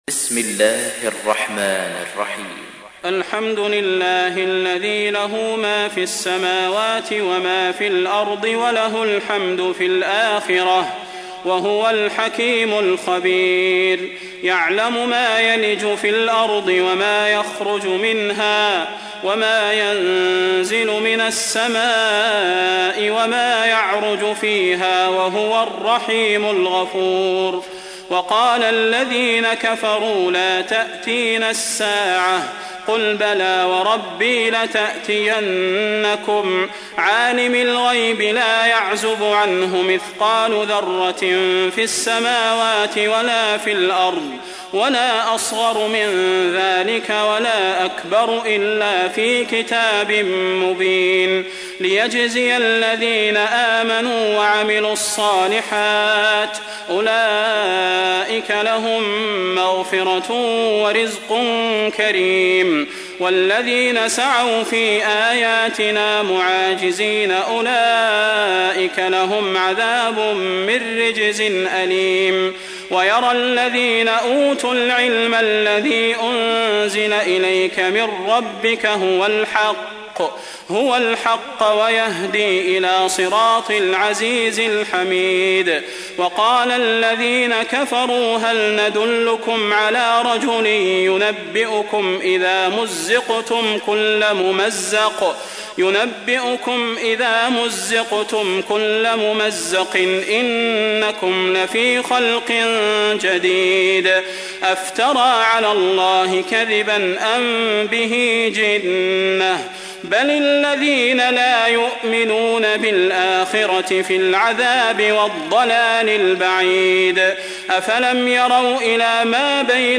تحميل : 34. سورة سبأ / القارئ صلاح البدير / القرآن الكريم / موقع يا حسين